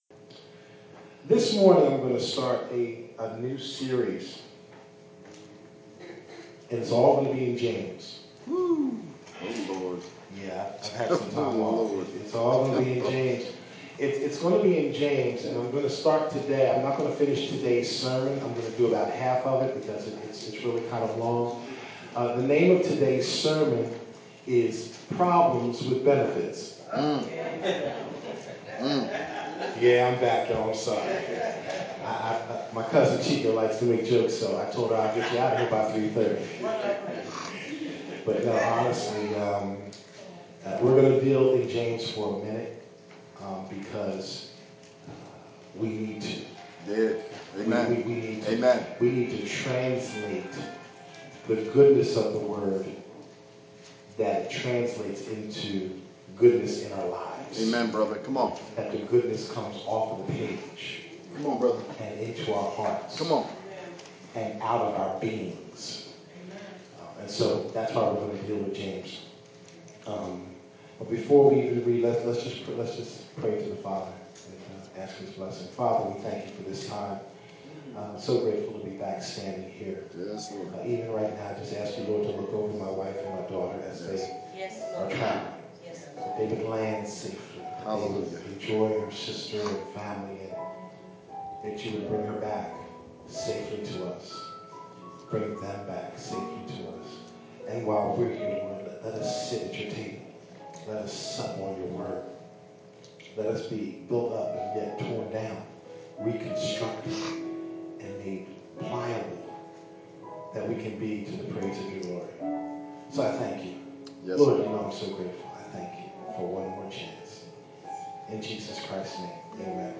The James Series Sermons Audio — FBCD